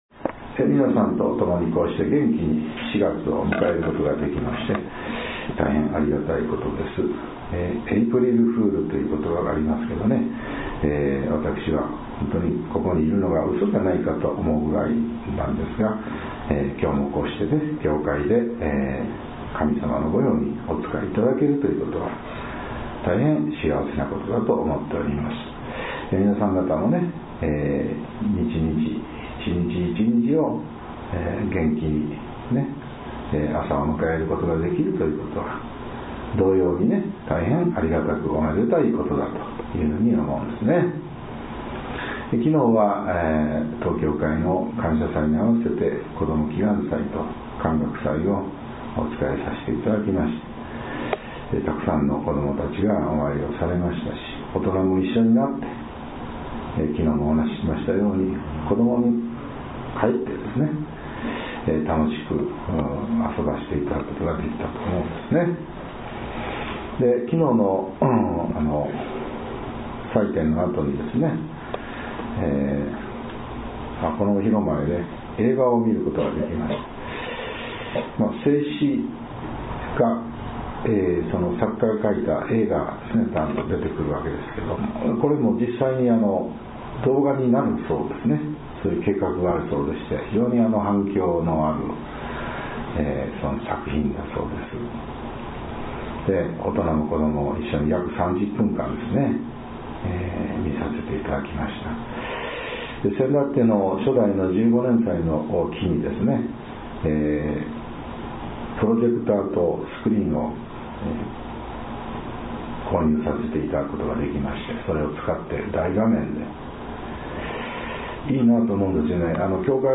子ども祈願祭より H30.4.1 | 悩み相談・願い事祈願「こころの宮」